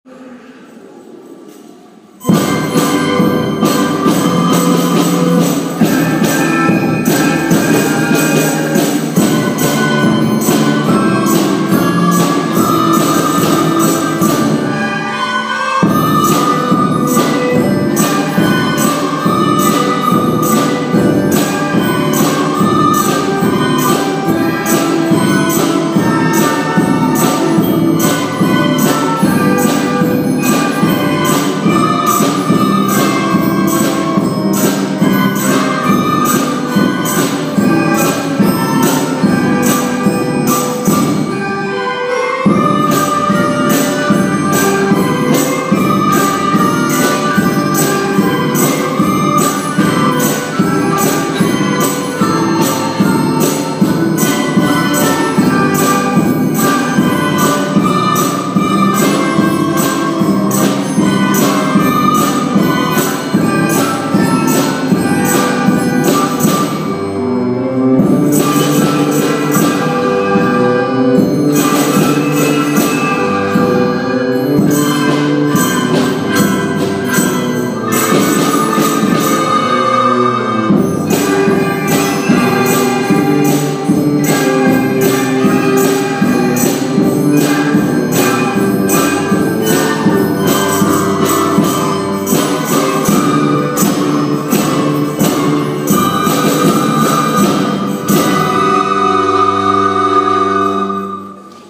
2016年6月4日（土）ふれあい科　１１ｔｈ大空創立記念コンサート
大空小では朝会などの退場曲として流れるなじみのあるこの曲を、３年生はリコーダー、４年生はいろいろな楽器で演奏します♪６学年の中で一番人数の少ない４年生は、中学年のリーダーとして人数の多い３年生を引っ張っていくぞと一生懸命演奏し、３年生は４年生の音を聴いて優しい音色を奏でていました。会場中がノリノリになるとっても素敵な演奏でした♪